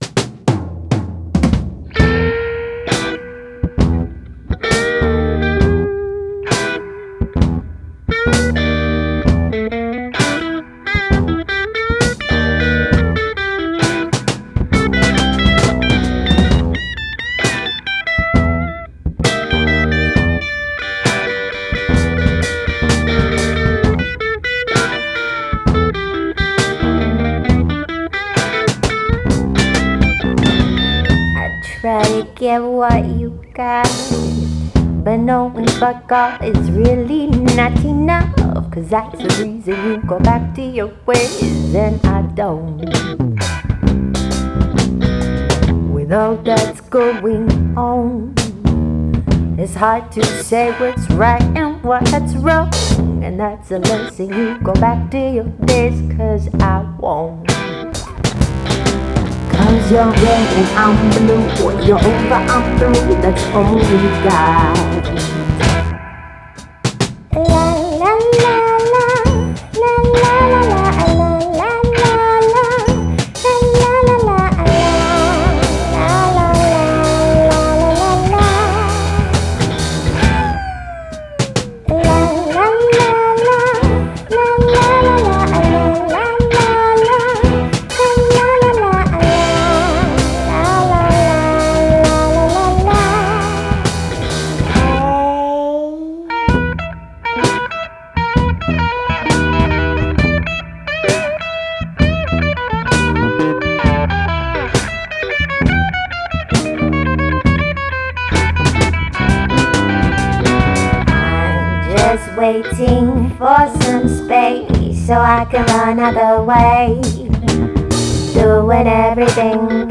Sydney-based quintet